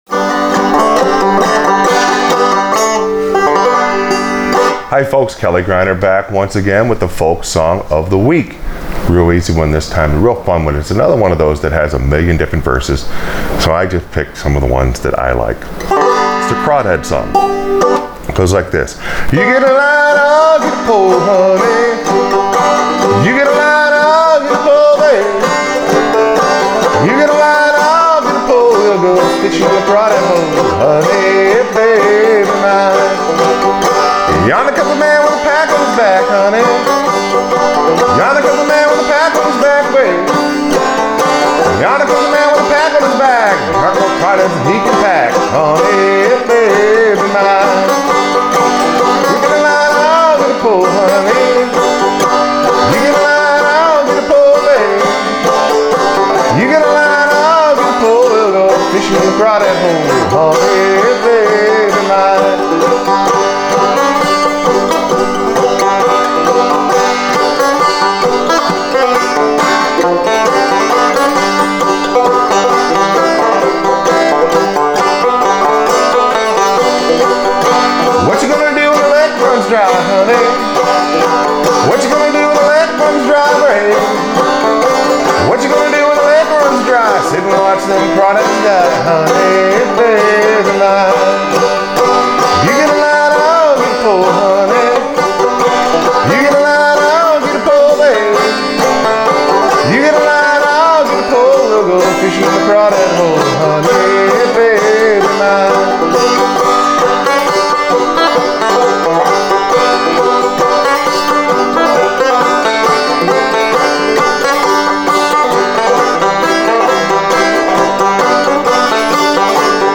Playing Frailing Banjo on this one is a lot of fun.
I went one way during the melody portion.